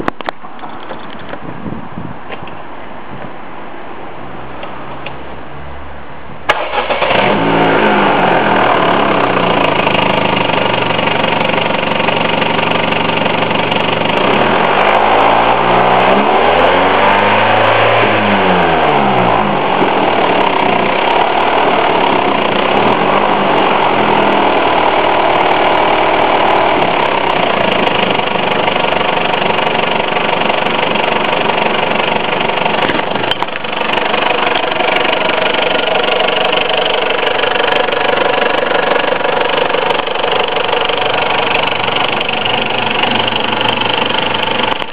Klickgeräusch beim Leerlauf
Ist mit defekter Kette und Spanner bei warmen Motor.
das klingt ja eher wie ein alter ford diesel